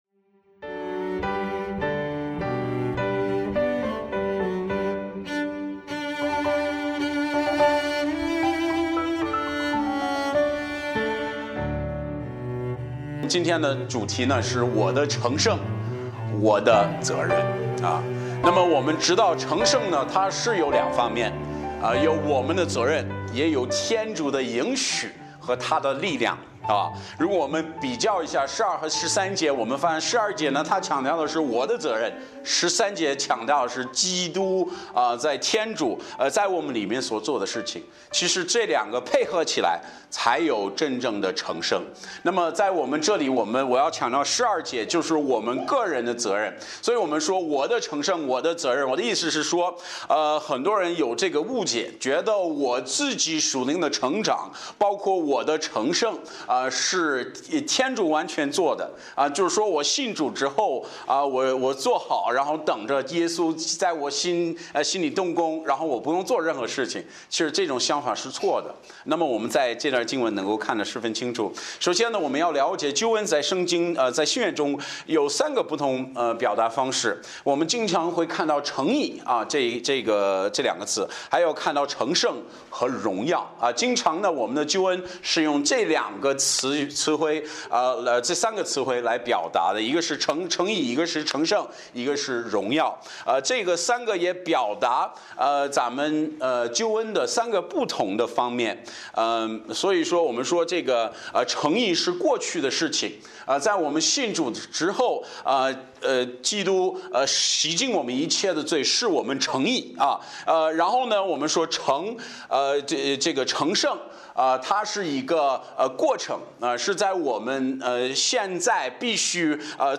Series: 周日礼拜